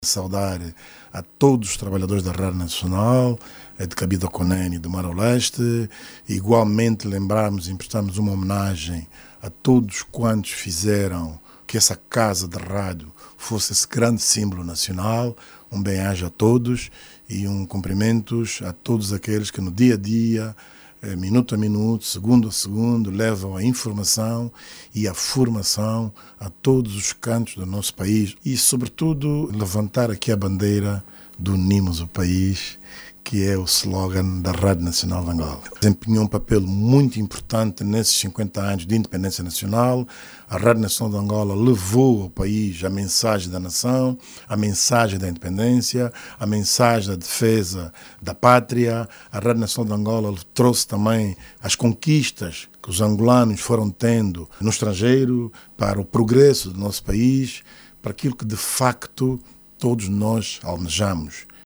O Ministro das Telecomunicações, Tecnologias de Informação e Comunicação Social, destacou ontem, domingo(05), os grandes feitos da RNA ao longo dos 50 anos de independência nacional. Mário Oliveira visitou os estúdios centrais, por ocasião do 5 de Outubro, dia da Rádio Nacional de Angola.